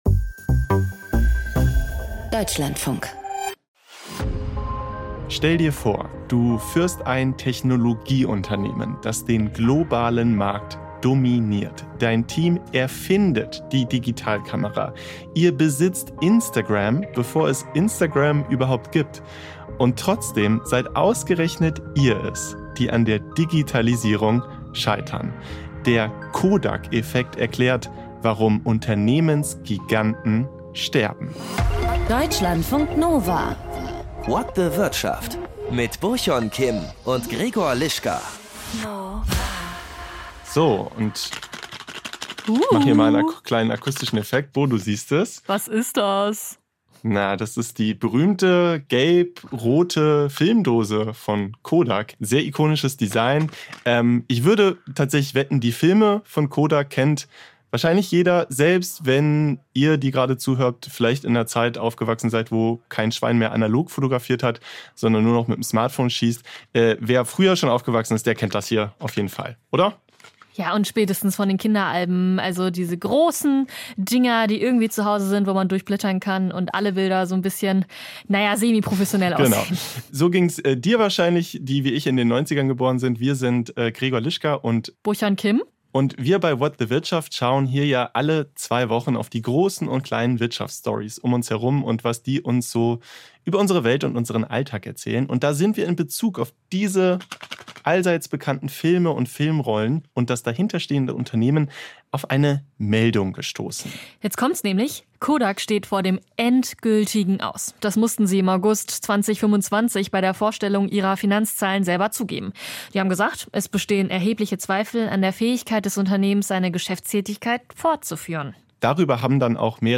Autorenfotos prägen das Image von Schriftstellern, sie werden auch zum Marketinginstrument. Ein Feature über die Bedeutung und den Stil der Bilder.